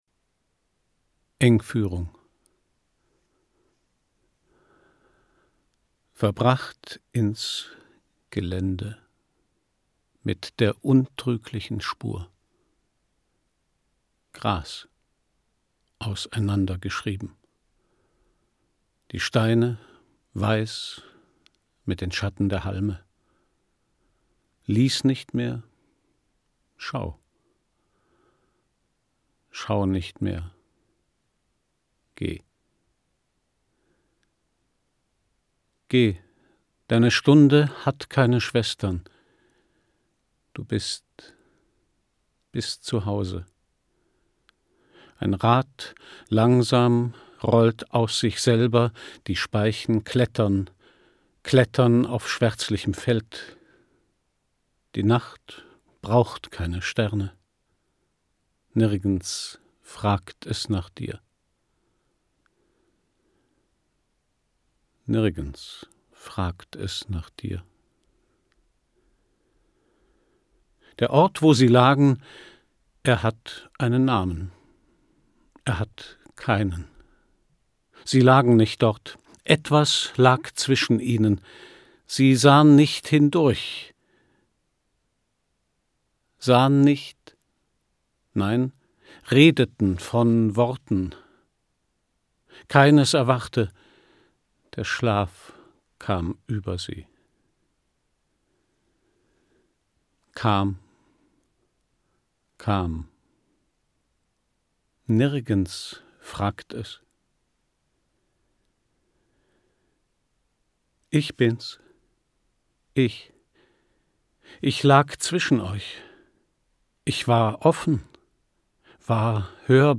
Gedichte
Christian Brückner (Sprecher)
Jahrhundert • AUDIO/Belletristik/Lyrik • Audio-CD • Audio-CD, Kassette / Belletristik/Lyrik • Celan • Christian Brückner • Gedichte • Hörbuch • Hörbücher • Hörbücher; Lyrik (Audio-CDs) • Hörbuch; Lyrik/Gedichte • Hörbuch; Lyrik/Gedichte (Audio-CDs) • Lyrik • Lyrik (Audio-CDs) • Lyrik/Gedichte (Audio-CDs)